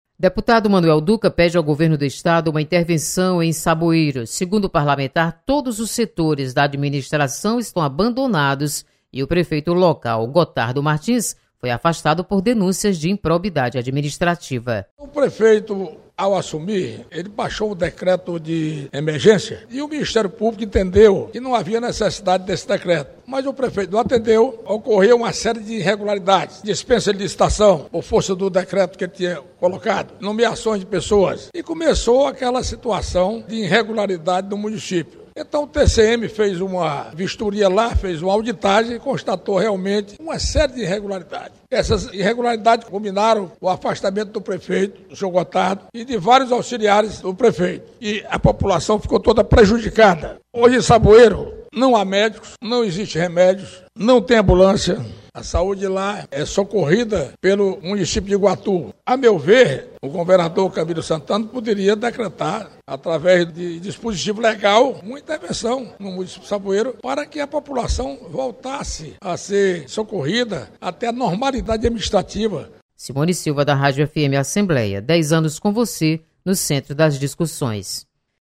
Plenário